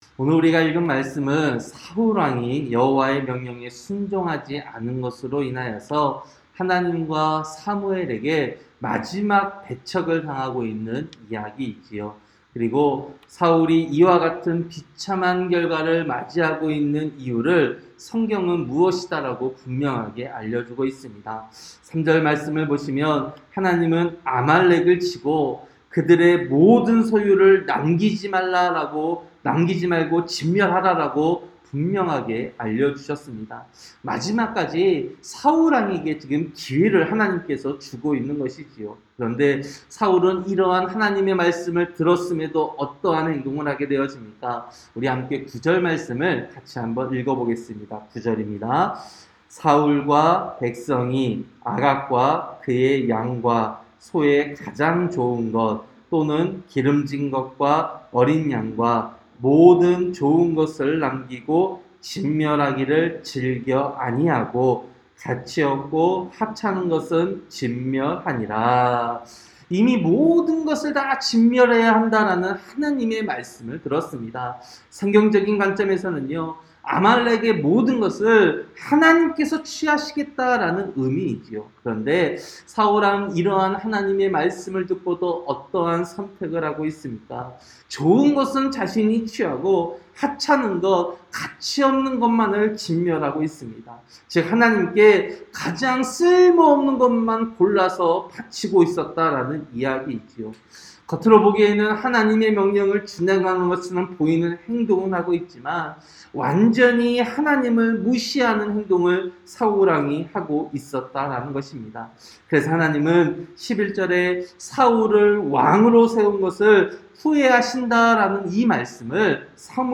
새벽설교-사무엘상 15장